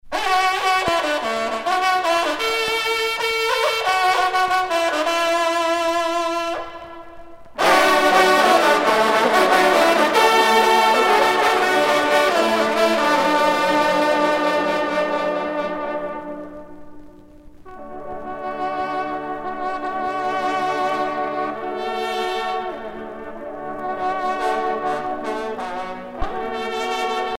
trompe - fanfare
circonstance : vénerie
Pièce musicale éditée